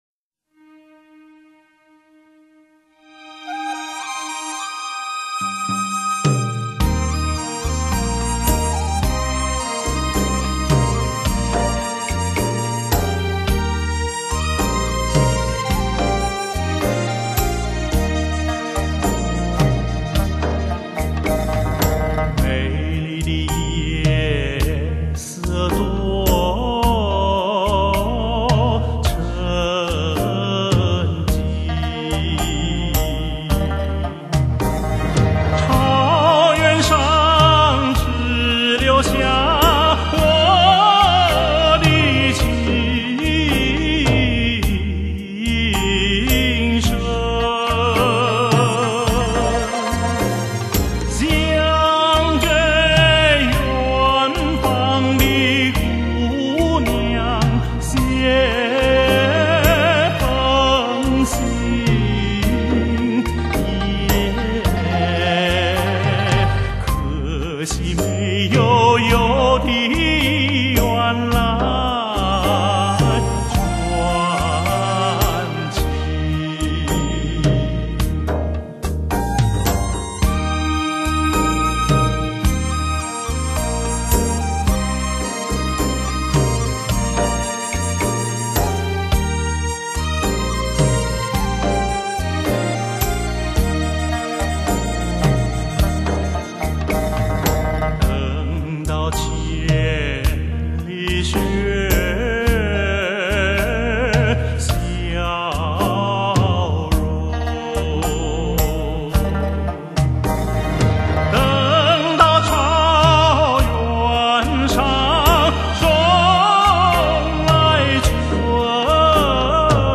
把通俗民歌艺术化，把艺术表演通俗化。